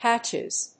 /ˈhætʃɪz(米国英語)/